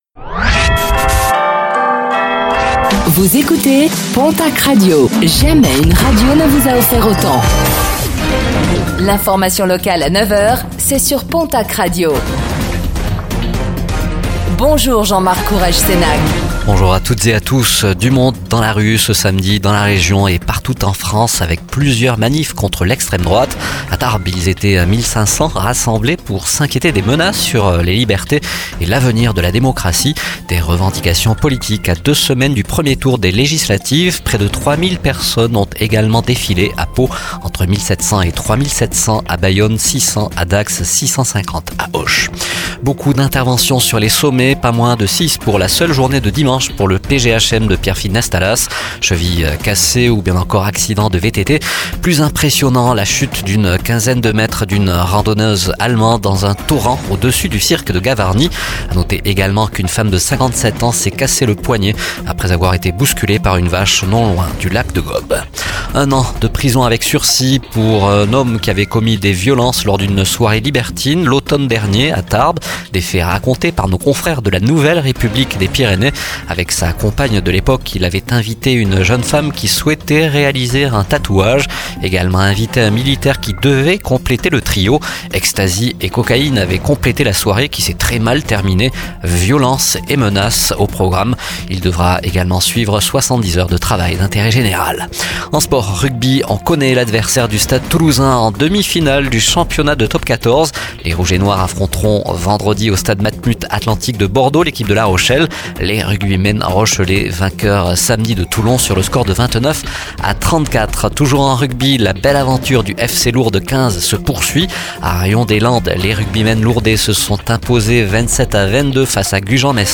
Réécoutez le flash d'information locale de ce lundi 17 juin 2024